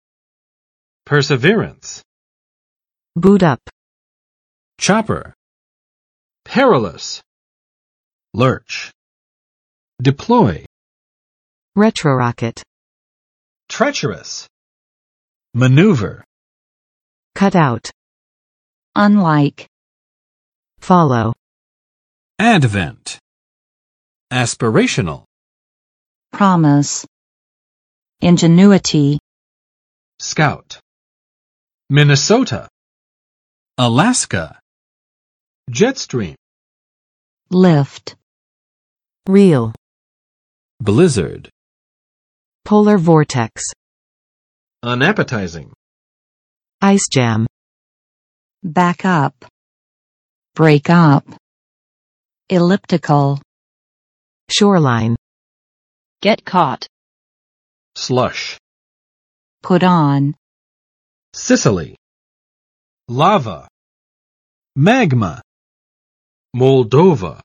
[͵pɝsəˋvɪrəns] n. 坚持不懈；坚忍不拔